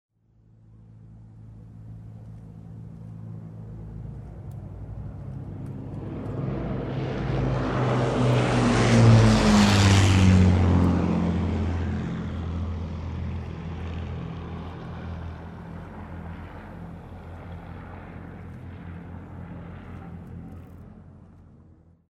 four Merlin engines filled the air and had those present scanning the sky looking for a non-existent Lancaster bomber.
Lancaster.mp3